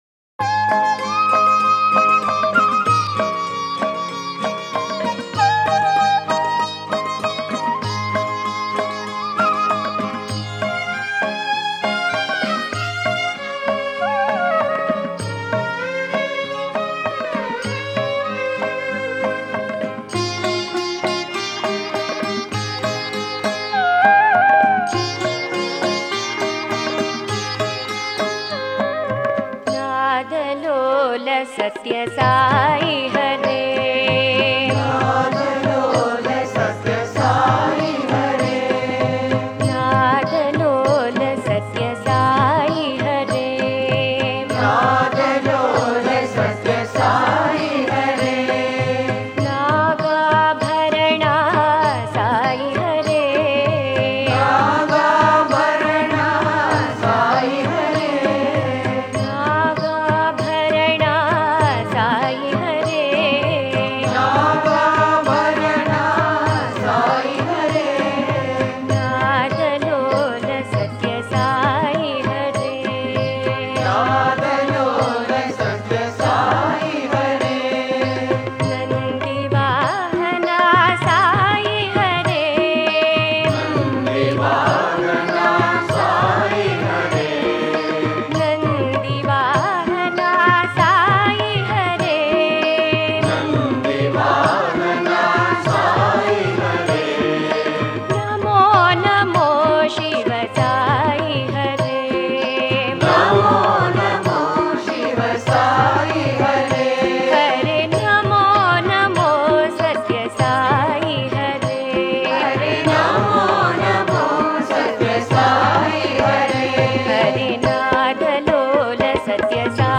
Author adminPosted on Categories Shiva Bhajans